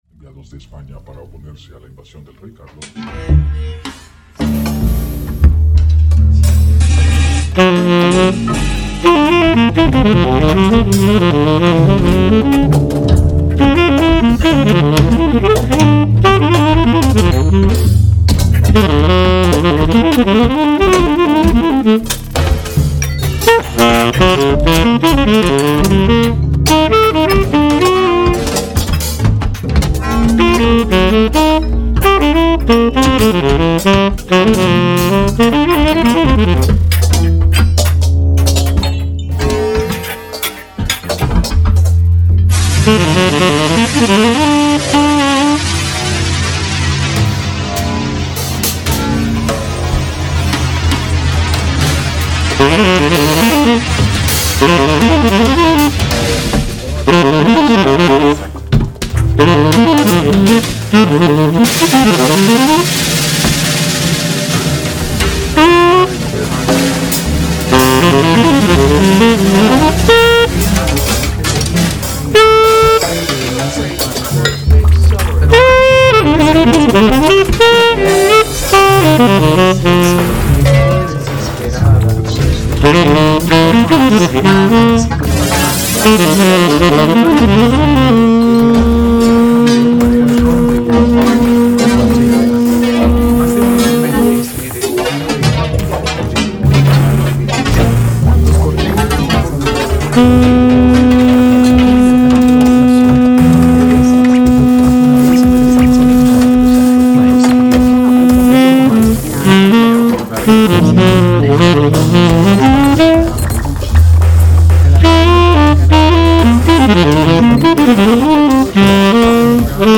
live streamed on 17 Oct 2025
saxophone
(Live mashed) sonic fictions